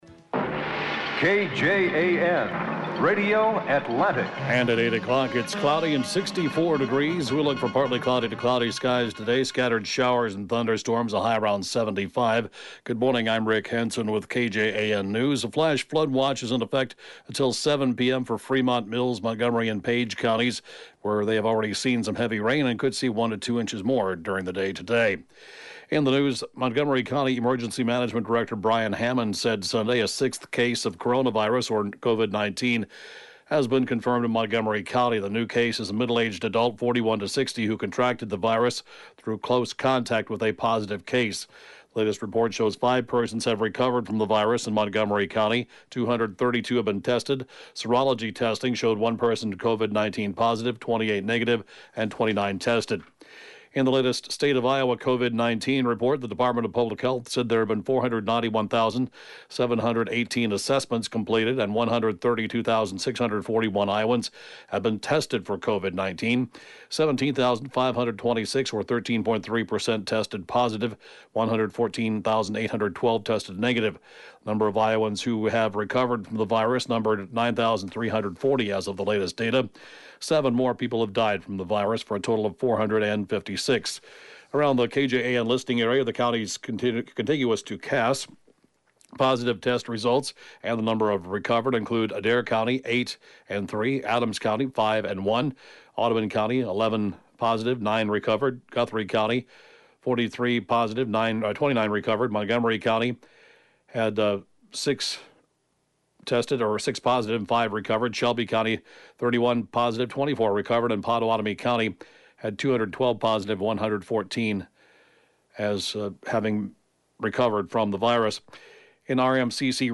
(Podcast) KJAN Morning News & Funeral report, 5/25/20